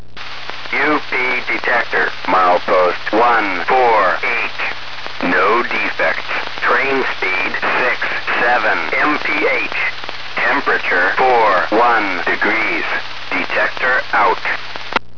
I used a Panasonic RN-402 mini-cassette recorder hooked directly to my ham radio (Yaesu FT-411E) or Uniden scanner (BC-200XLT) to record.
Detectors Heard From My House
Milepost 148 - Sacramento Subdivision, Freq: 161.550, 12 mi. away due West near the Del Paso siding.